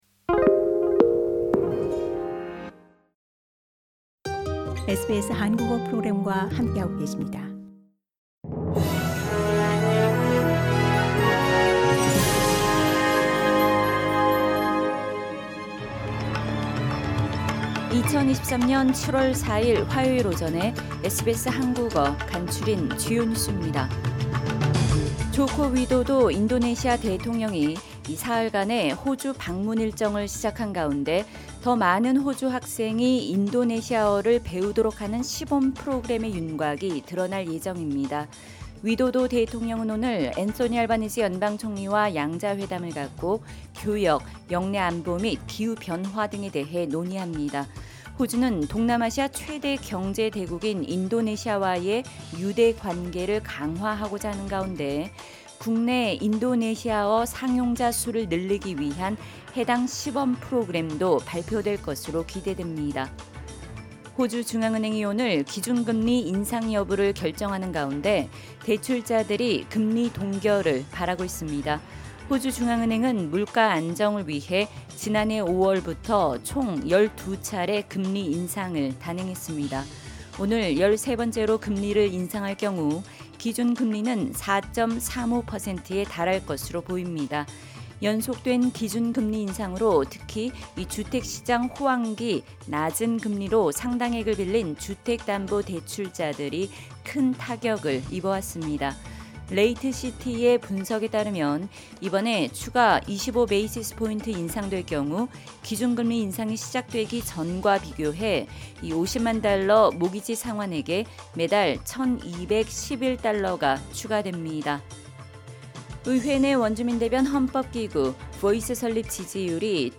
SBS 한국어 아침 뉴스: 2023년 7월 4일 화요일